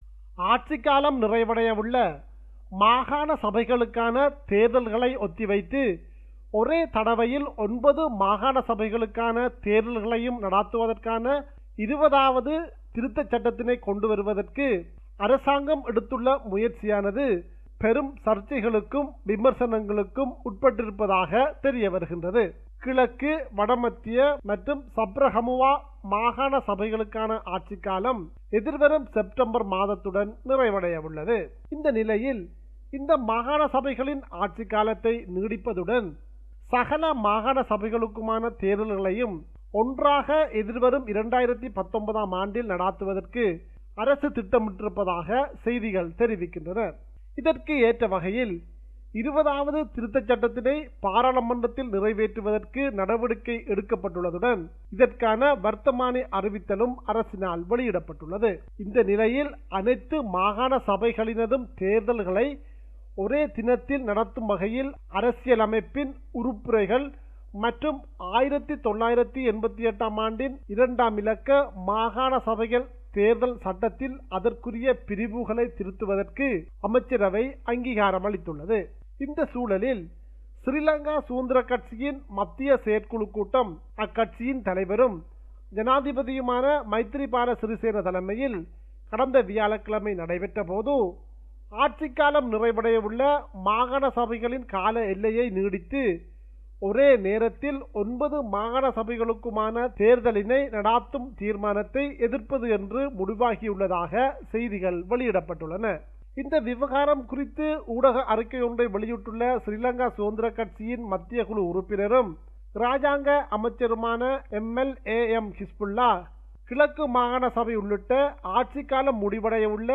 our correspondent in Sri Lanka, compiled a report focusing on major events in Sri Lanka.